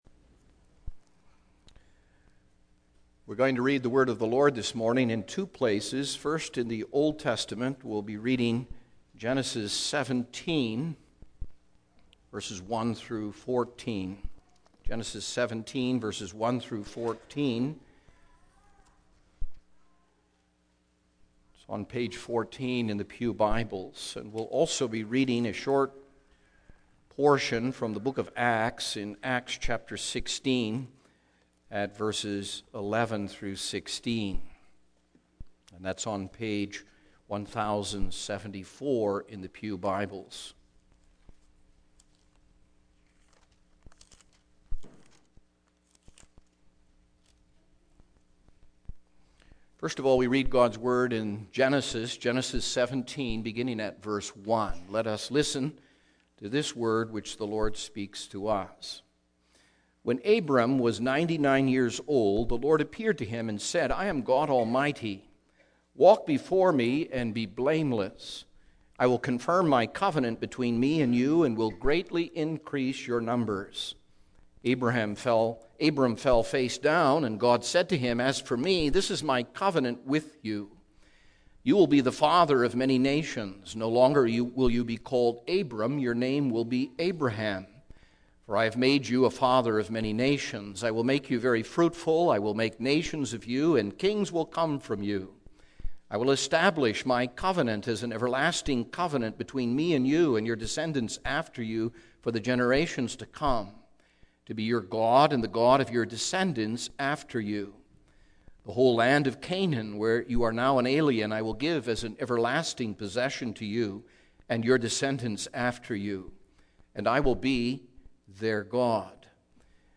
Single Sermons Passage